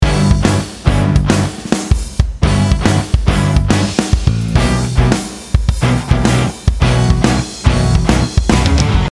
1st Verse – introducing piano, strings and guitar, the instruments that will be used throughout this track.
The bridge itself is mainly based on the exploration of this rhythmic cell: